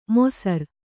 ♪ mosar